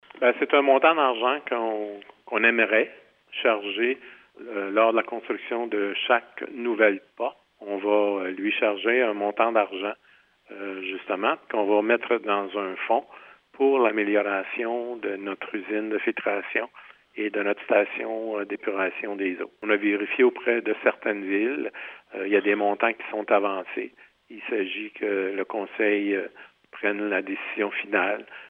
Waterloo prévoit dès l’an prochain mettre en place une redevance au développement et cette contribution sera payée par les propriétaires de nouvelles constructions. Les détails à ce sujet avec le maire de Waterloo, Jean-Marie Lachapelle : Waterloo, demande eau redevances 2025, 04.12.24_Lachapelle, clip